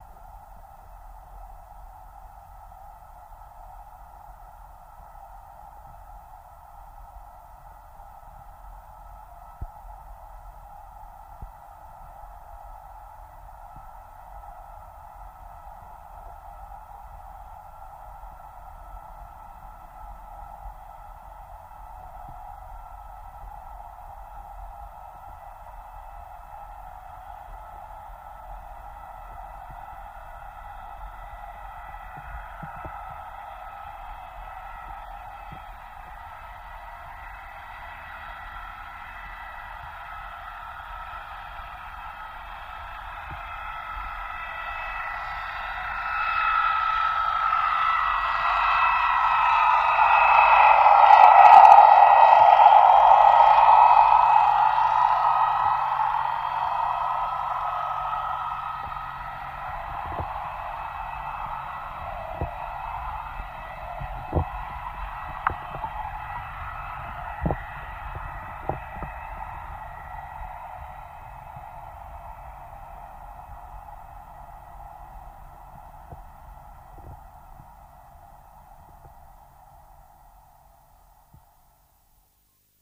Torpedo Jet By